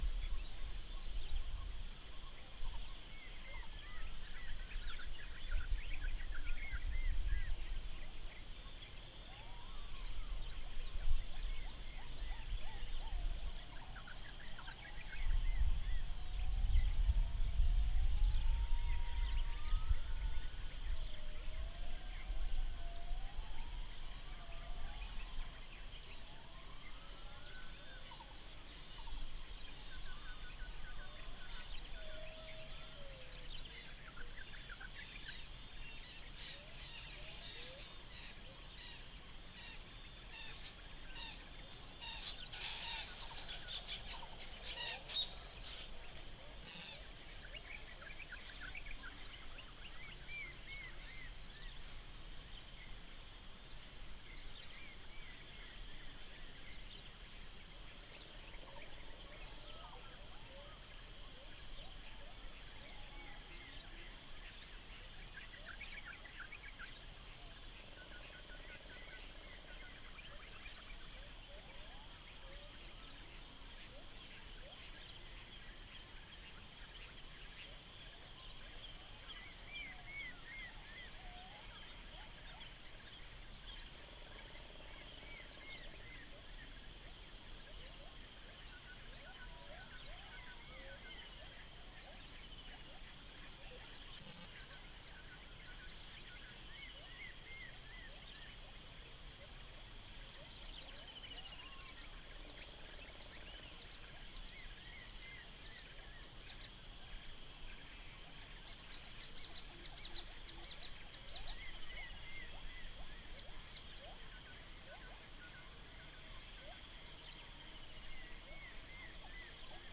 Projects SoundEFForTS Berbak NP phase 1 and 2 B01
Psilopogon chrysopogon
Centropus sinensis
Gracula religiosa
Pycnonotus plumosus